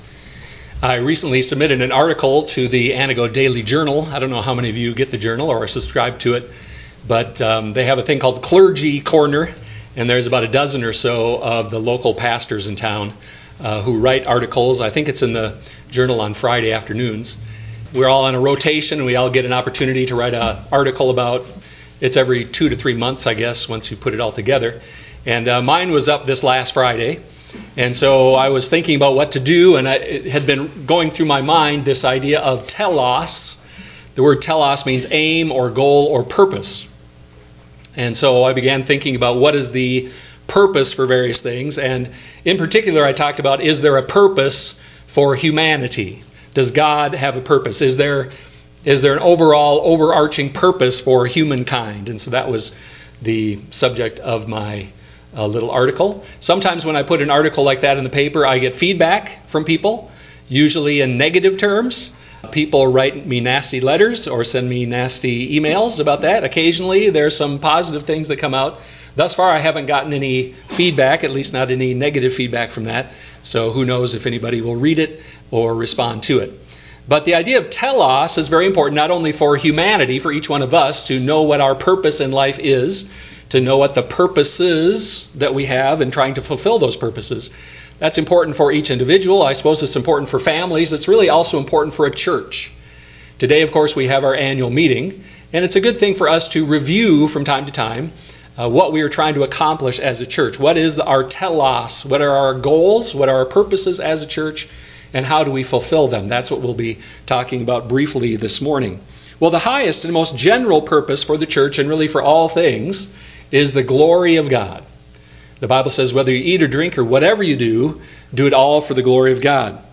1 Peter 4:1-11 Service Type: Sunday morning worship service Churches also have a telos—a goal